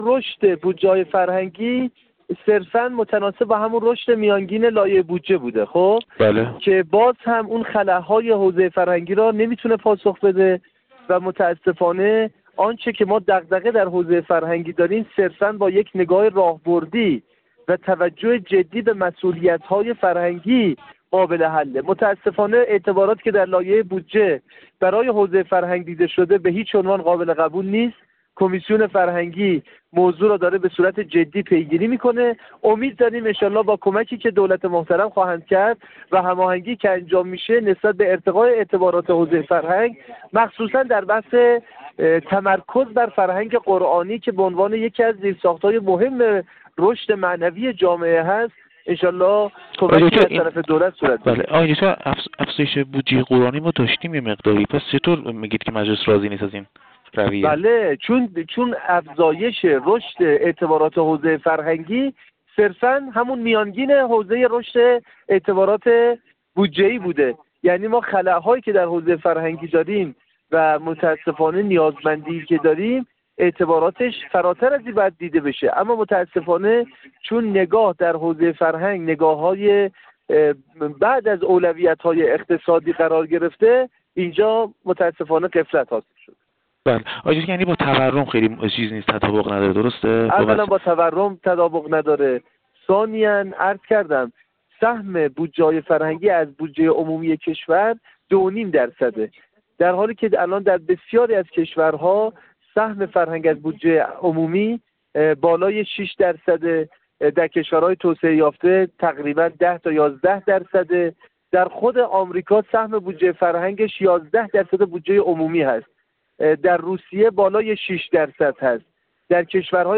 راستینه در گفت‌وگو با ایکنا مطرح کرد: